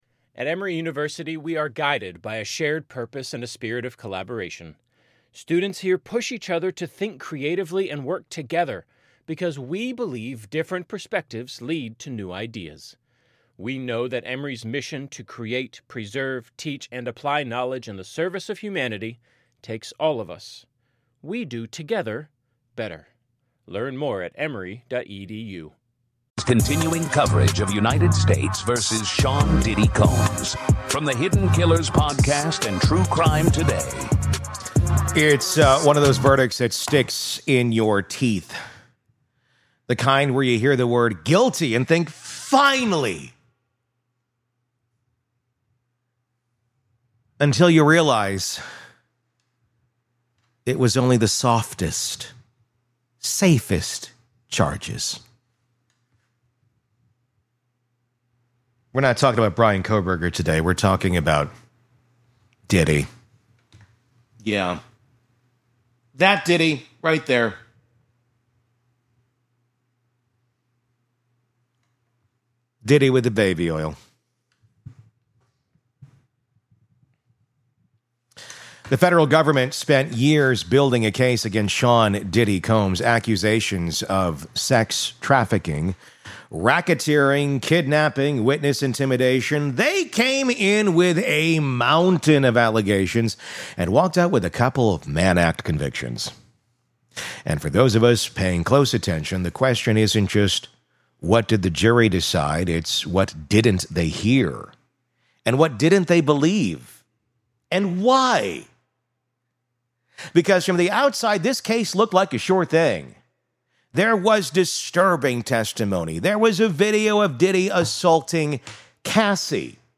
So what exactly went wrong? In this episode, we sit down with criminal defense attorney and former prosecutor